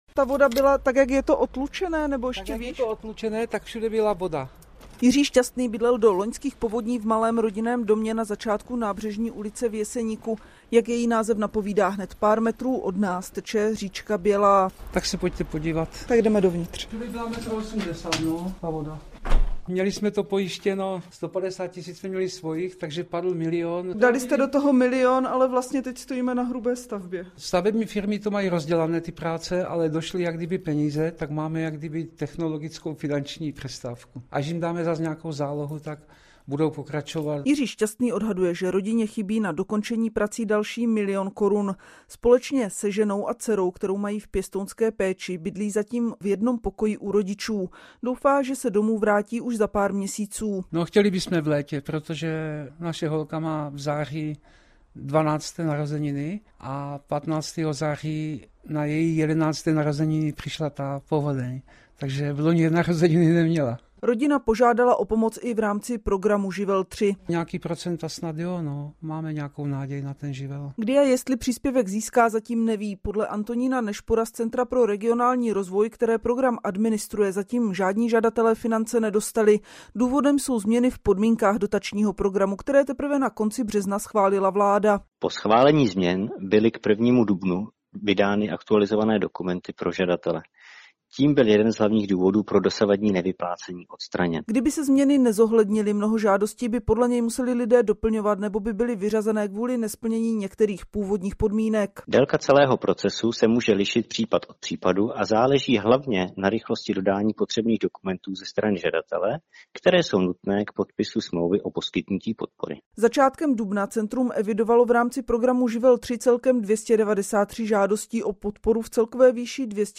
Zprávy ČRo Olomouc: Cestování mezi Přerovem a Bystřicí pod Hostýnem komplikuje uzavírka mostu v Dřevohosticích - 02.06.2025